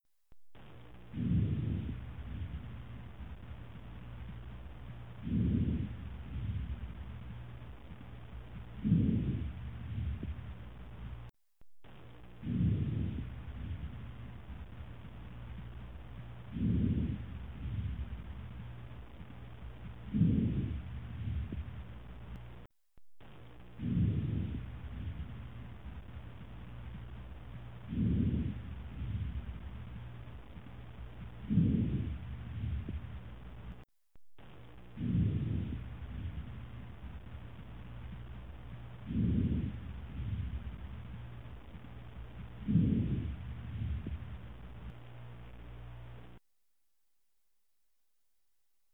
Normal Lung Sounds